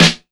• Raw Snare Sample E Key 86.wav
Royality free acoustic snare tuned to the E note. Loudest frequency: 2411Hz
raw-snare-sample-e-key-86-dEg.wav